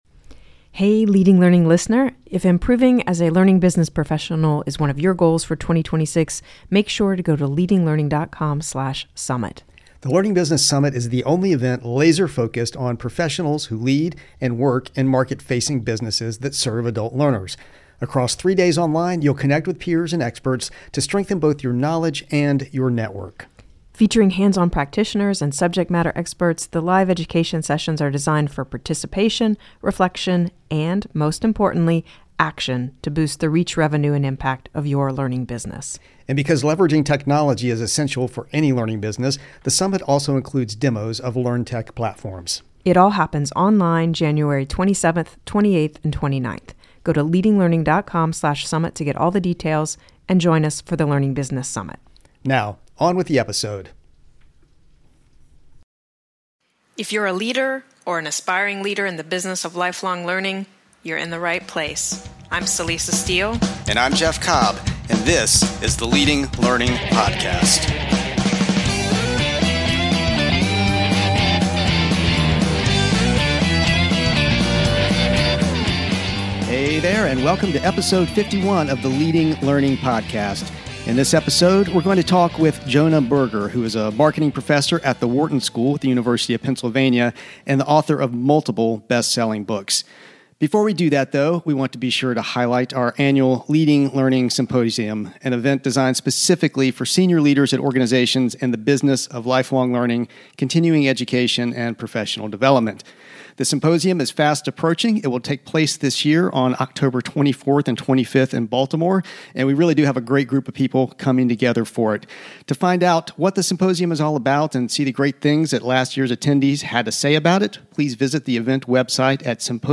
An interview with Jonah Berger about what drives people to share ideas, ways to influence behavior, and how social influence impacts learning.